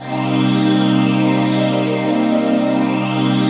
DM PAD2-70.wav